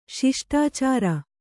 ♪ śiṣṭācāra